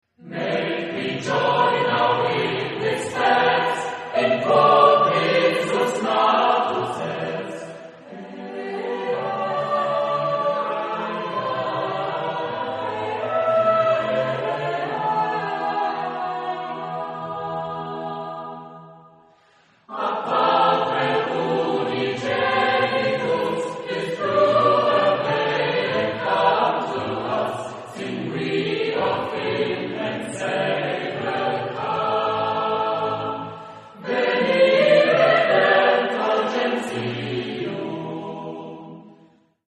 Genre-Style-Form: Sacred ; Christmas carol
Mood of the piece: fast ; festive ; rhythmic
Type of Choir: SATB  (4 mixed voices )
Tonality: E minor